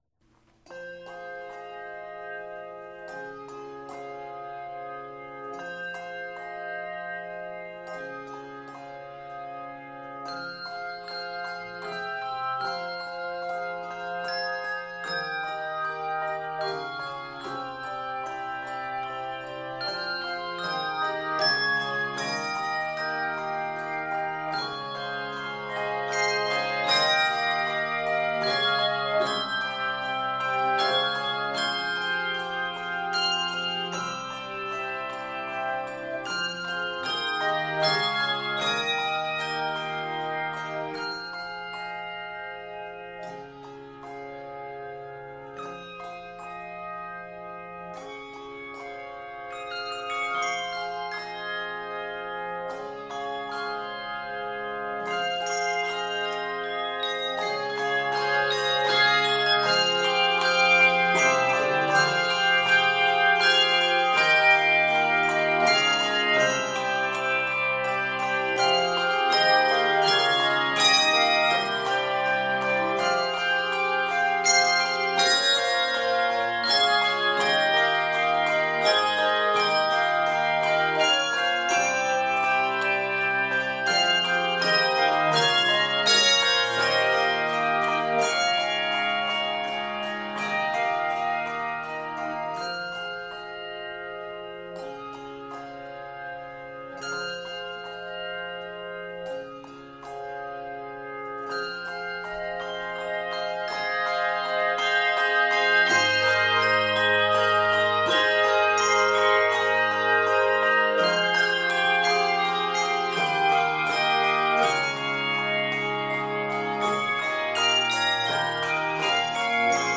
In this expressive, challenging piece
Key of f minor.